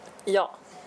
はい (HAI) Yes. 　 Ja (ヤー)